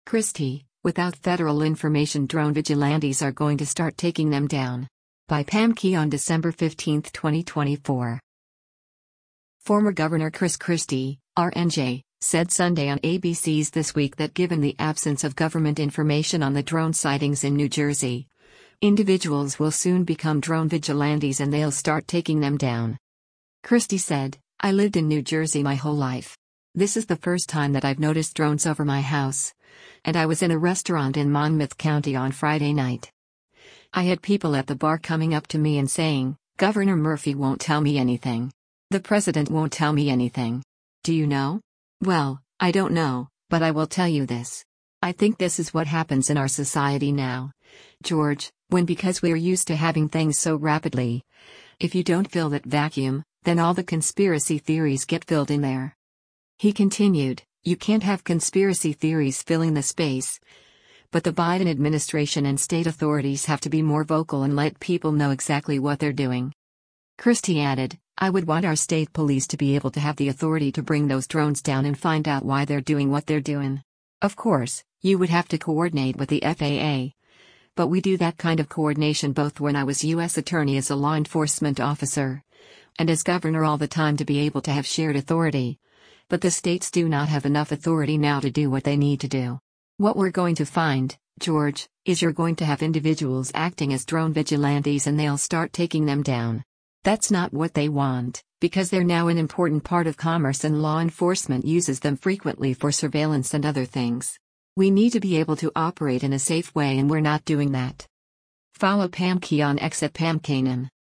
Former Gov. Chris Christie (R-NJ) said Sunday on ABC’s “This Week” that given the absence of government information on the drone sightings in New Jersey, individuals will soon become “drone vigilantes” and “they’ll start taking them down.”